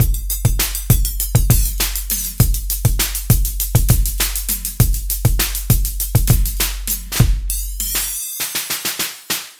80年代的嘻哈鼓
标签： 复古 hiphop_80年代_stlye
声道立体声